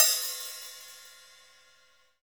Index of /90_sSampleCDs/Roland L-CD701/KIT_Drum Kits 8/KIT_Dance Kit
HAT LITE H07.wav